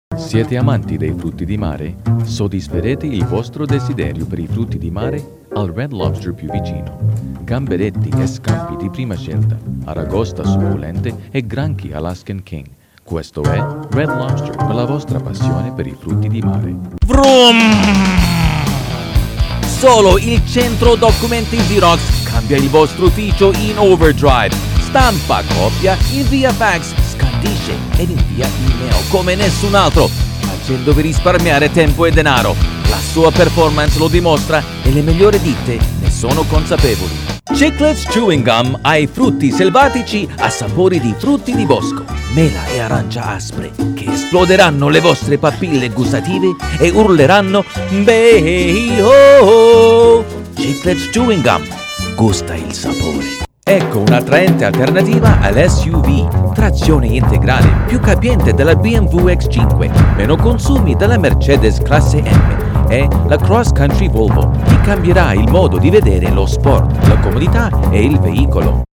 Italian Commercial/Animated Sample